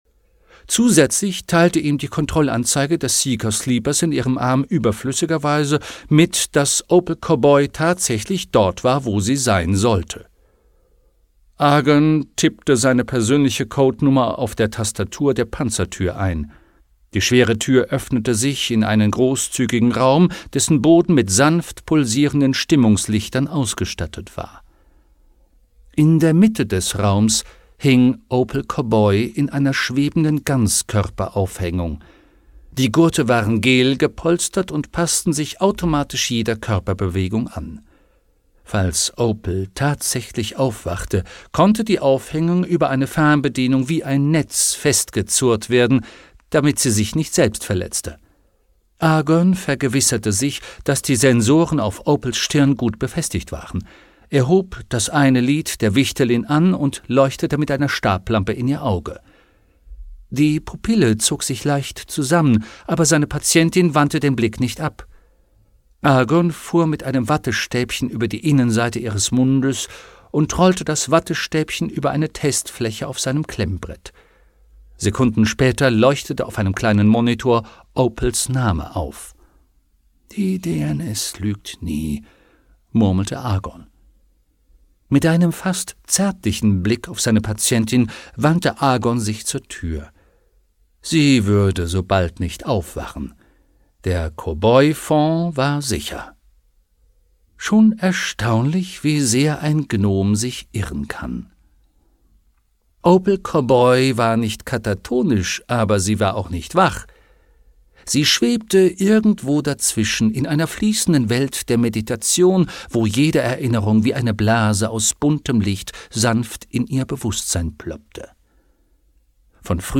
Hörbuch Artemis Fowl - Die Rache (Ein Artemis-Fowl-Roman 4), Eoin Colfer.